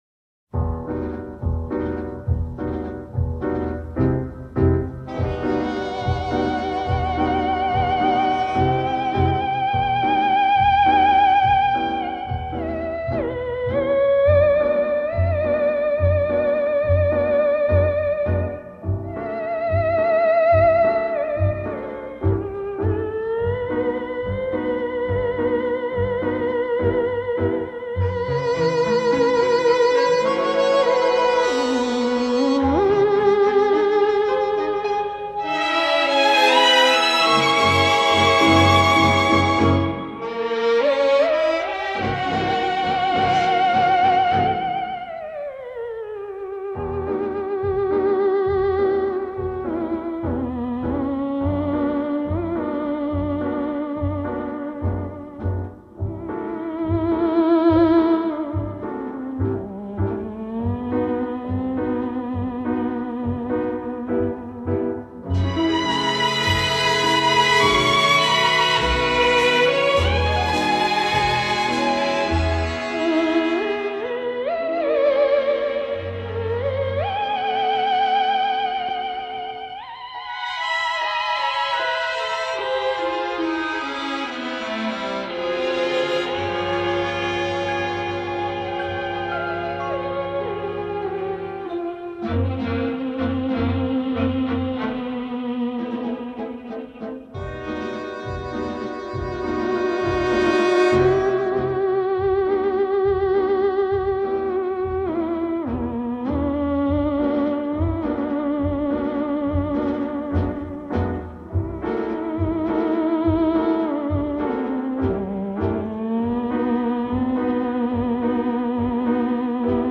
OLED SIIN ▶ muusika ▶ Lounge